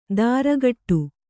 ♪ dāragaṭṭu